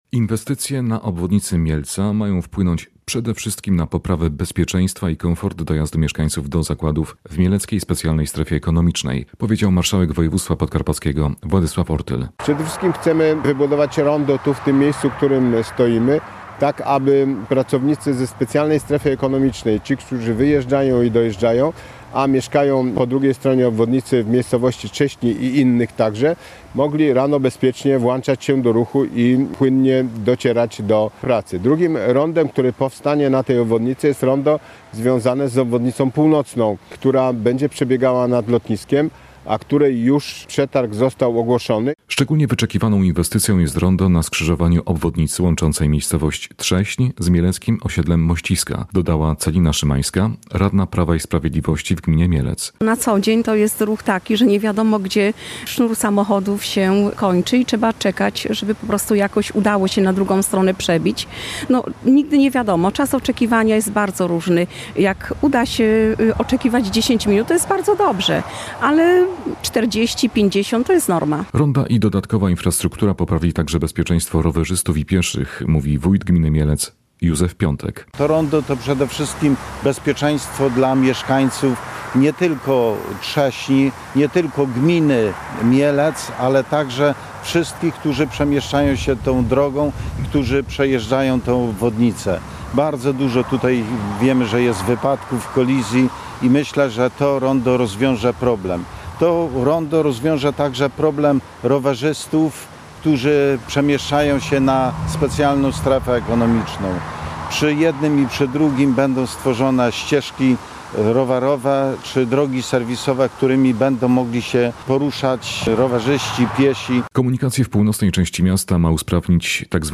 – powiedział marszałek Władysław Ortyl.